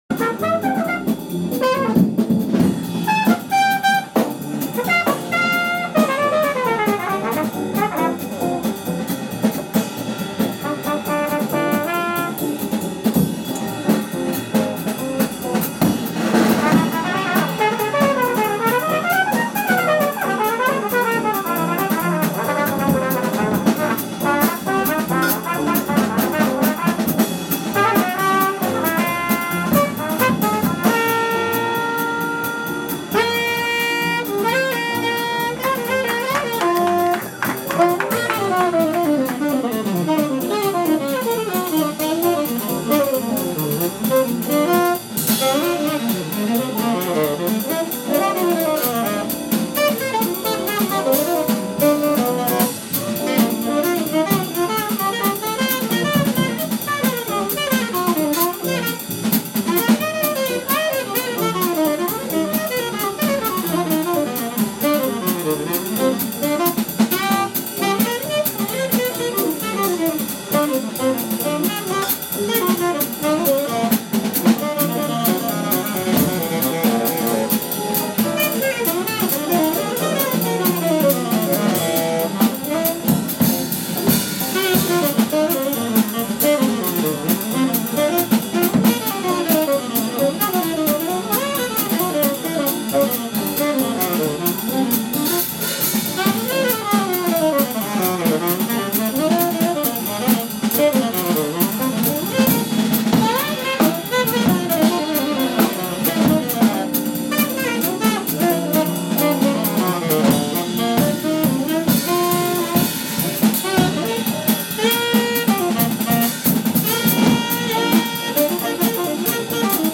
Live jazz